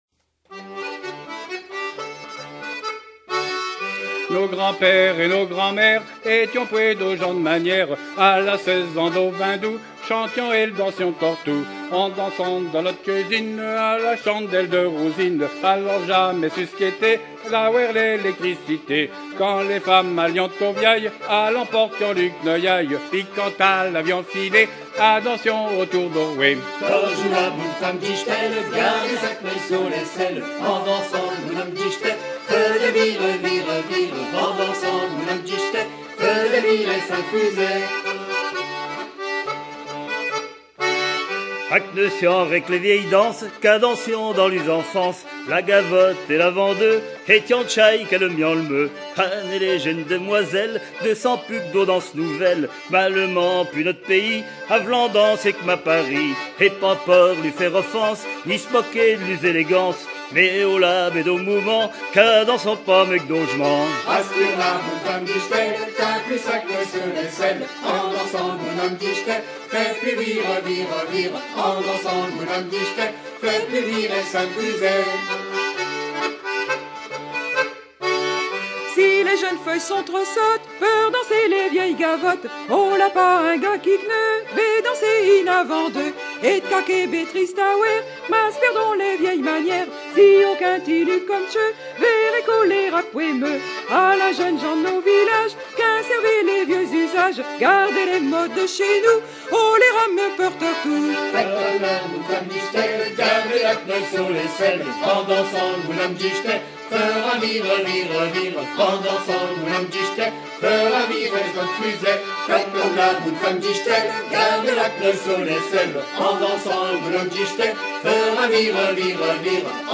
Patois local
Pièce musicale inédite